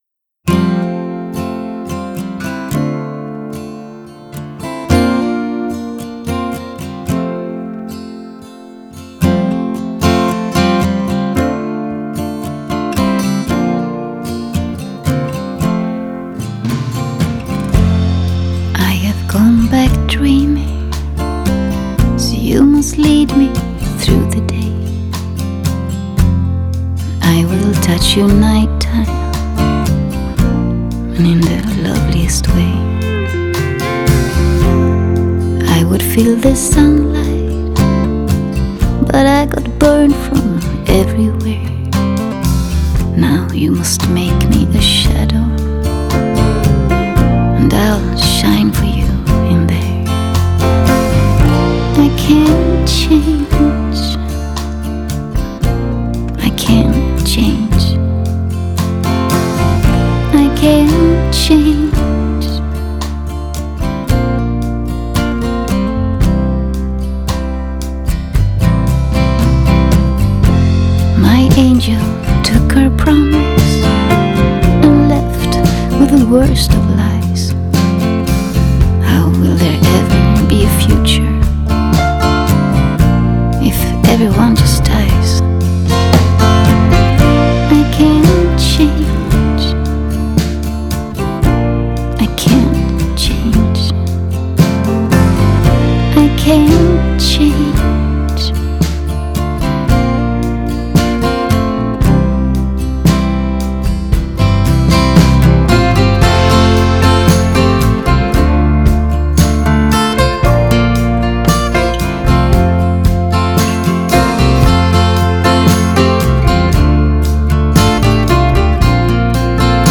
장르: Rock
스타일: Acoustic